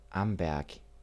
Amberg (German pronunciation: [ˈambɛʁk]